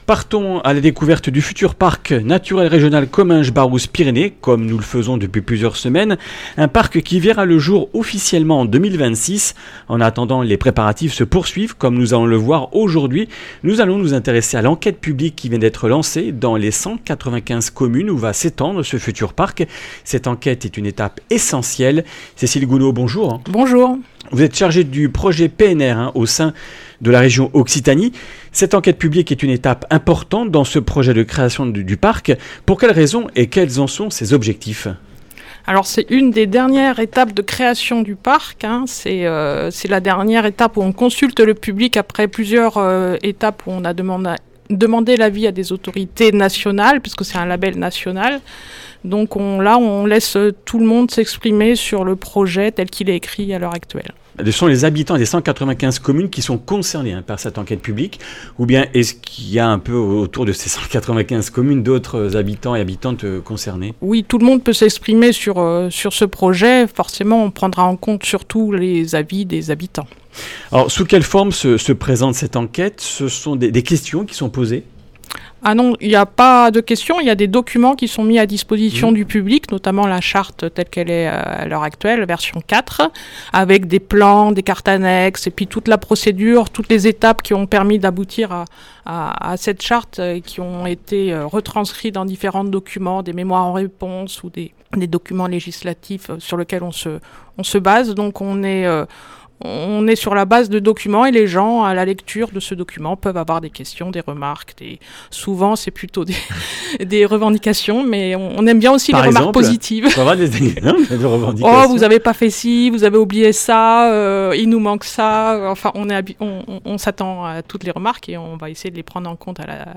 Comminges Interviews du 26 mars
Une émission présentée par